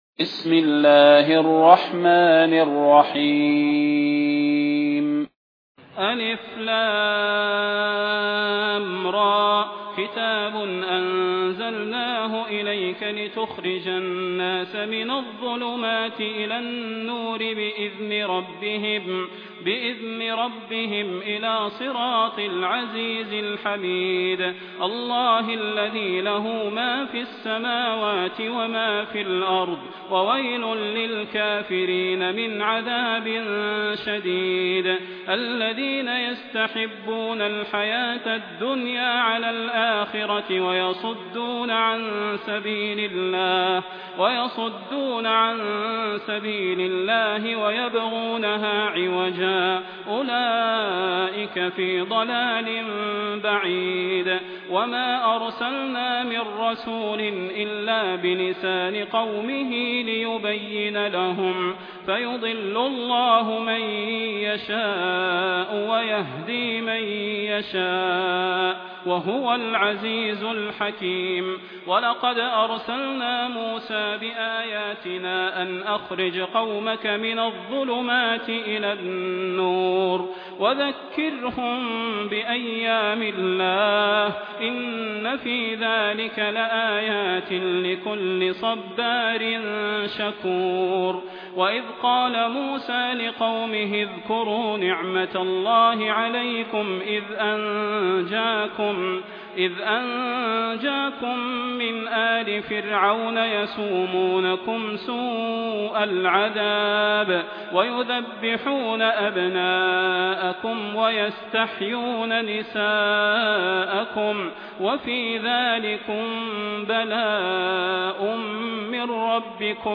المكان: المسجد النبوي الشيخ: فضيلة الشيخ د. صلاح بن محمد البدير فضيلة الشيخ د. صلاح بن محمد البدير إبراهيم